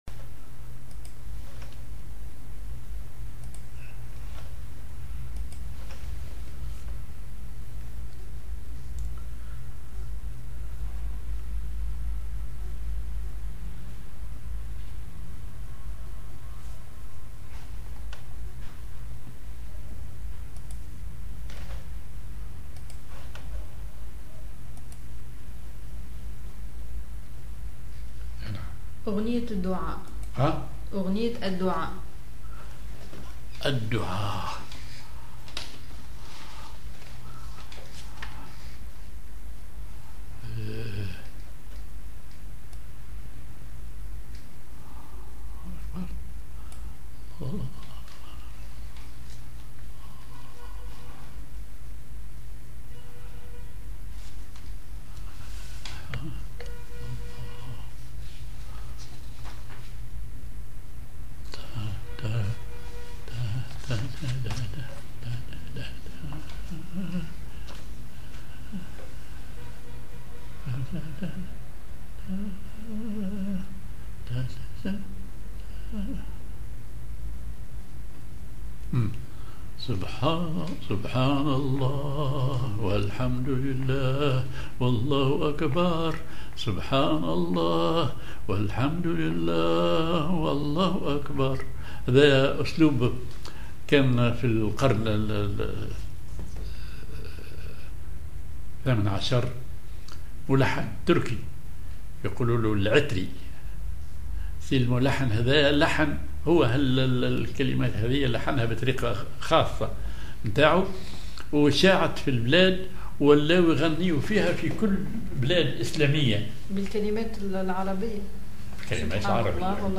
Maqam ar هزام على درجة السيكاه
genre أغنية